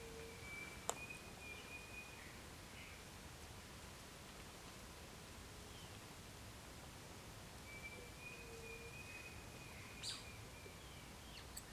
Yasiyateré Chico (Dromococcyx pavoninus)
Nombre en inglés: Pavonine Cuckoo
Fase de la vida: Adulto
Localidad o área protegida: Reserva Privada y Ecolodge Surucuá
Condición: Silvestre
Certeza: Vocalización Grabada